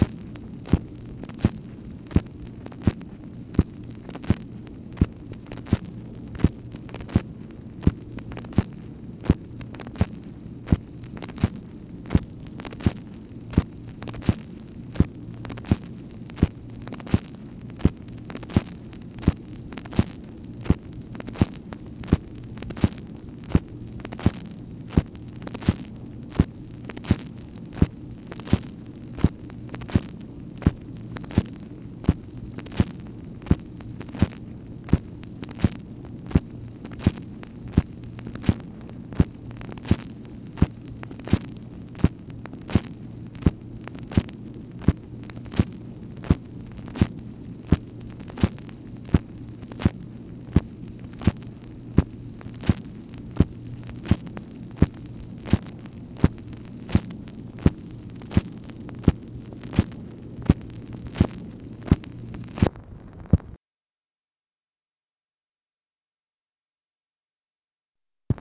MACHINE NOISE, September 14, 1965
Secret White House Tapes | Lyndon B. Johnson Presidency